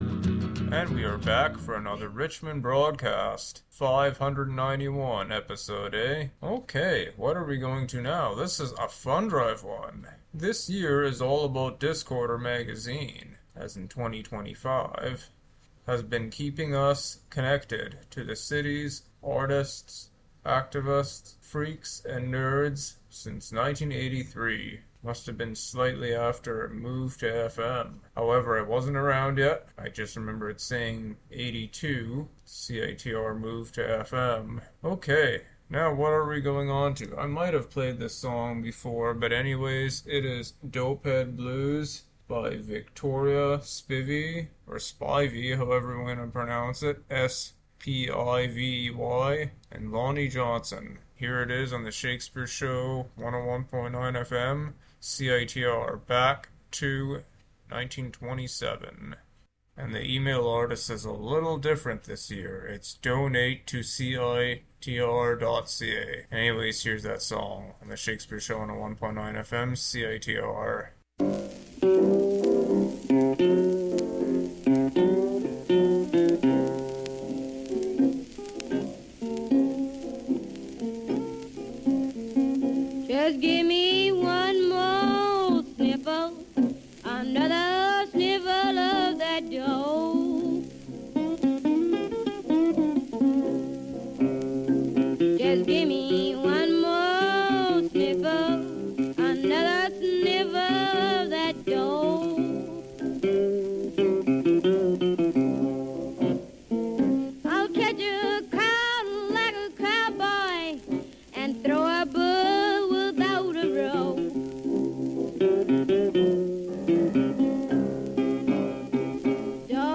an eclectic mix of music and some fundrive comments